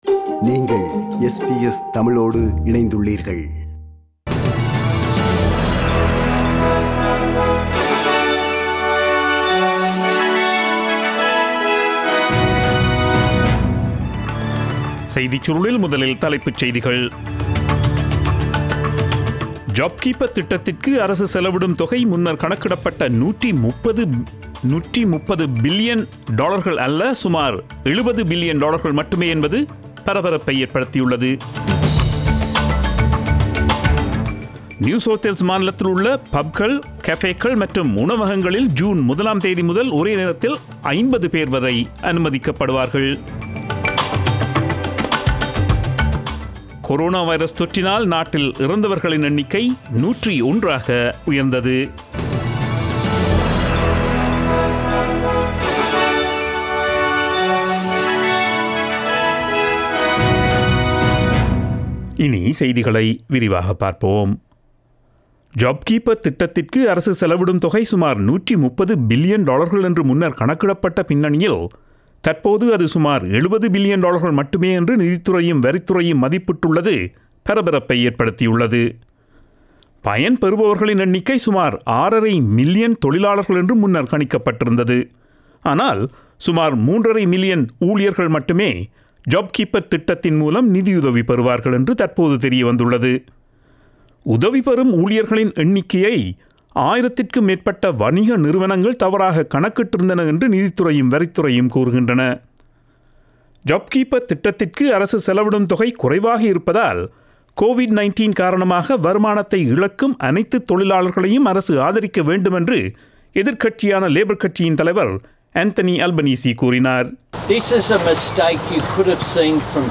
Australian news bulletin aired on Friday 22 May 2020 at 8pm.